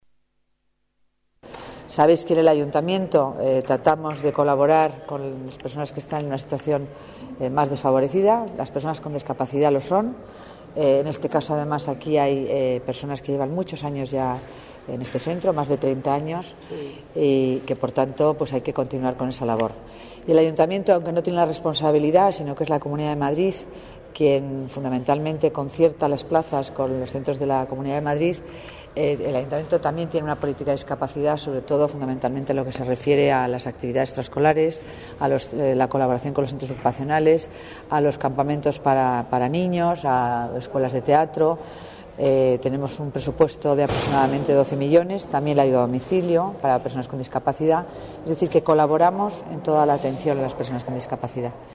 Nueva ventana:Declaraciones Concepción Dancausa